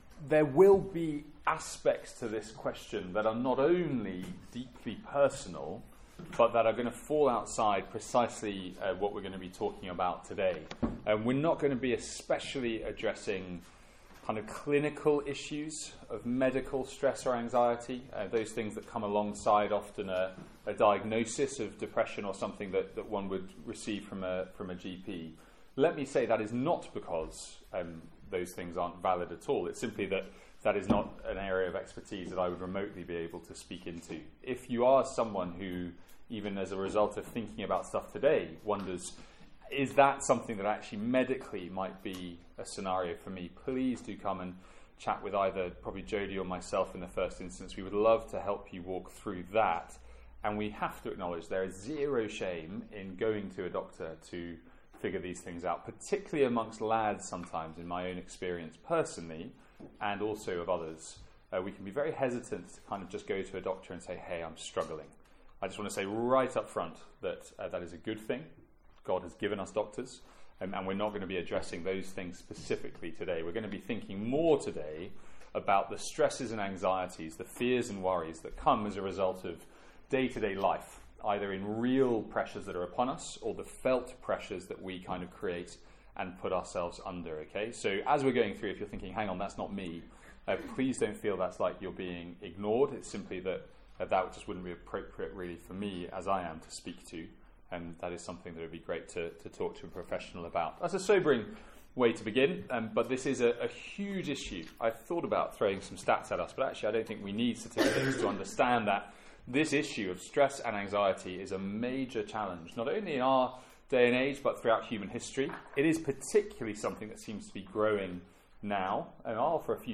From our second Student Lunch of the academic year.